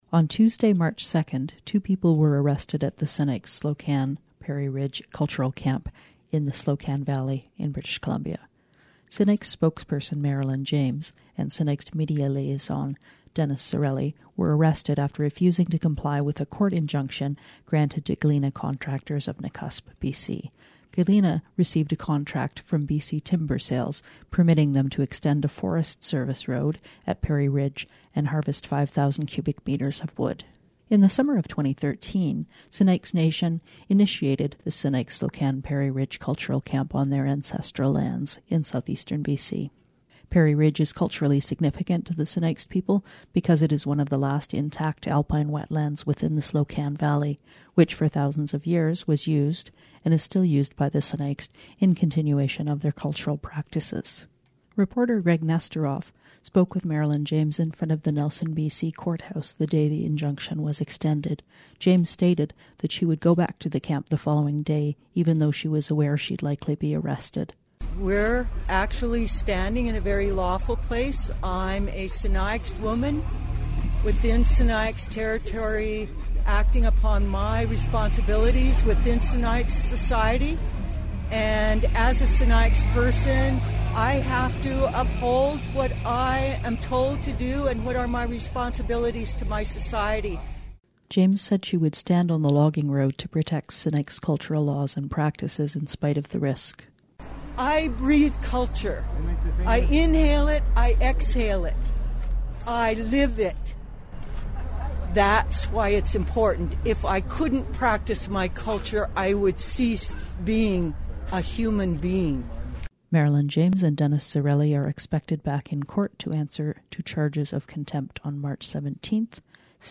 Recording Location: Nelson, BC
0kbps Stereo